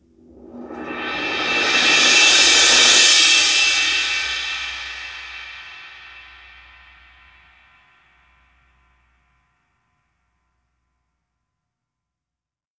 Crashes & Cymbals
18inch1barSwell.wav